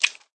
click_chink.ogg